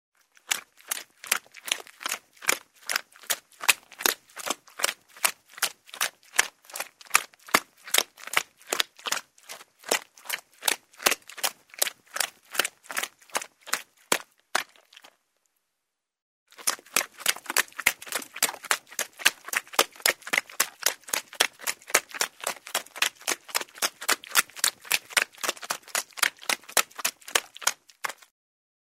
Звуки чавканья
Чавканье и плямканье